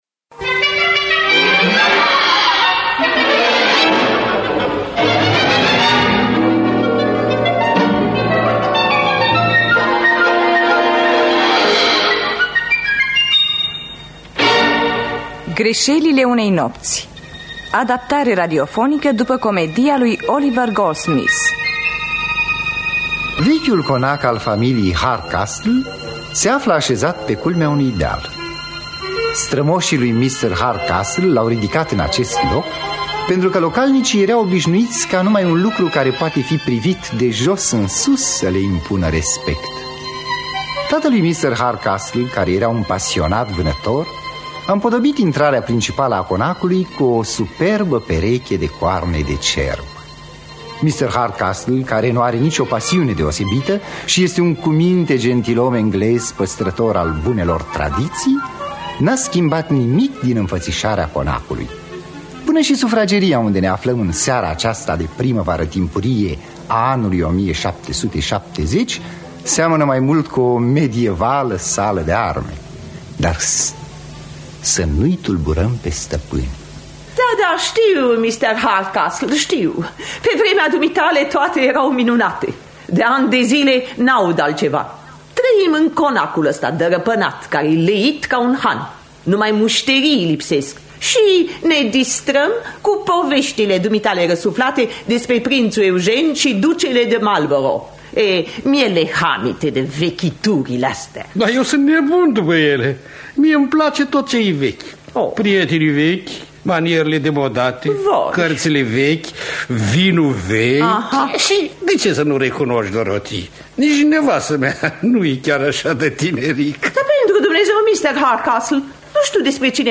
Greșelile unei nopți de Oliver Goldsmith – Teatru Radiofonic Online